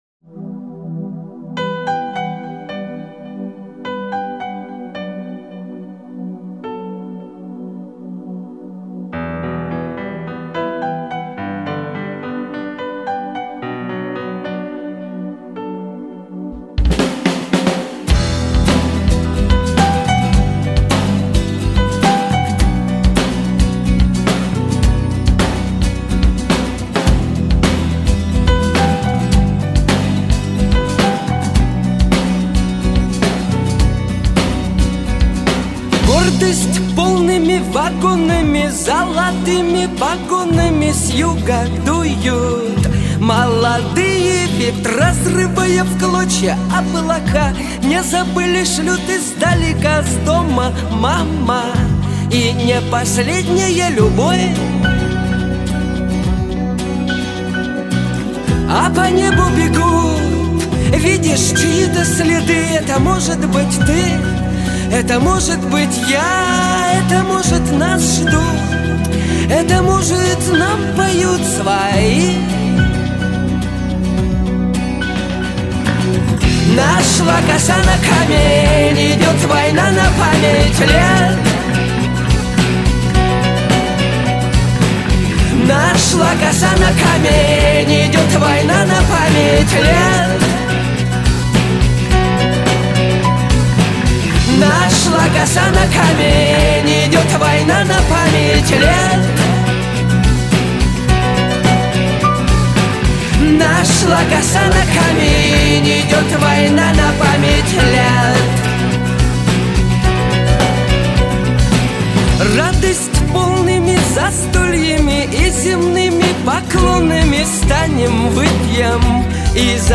Такт 4/4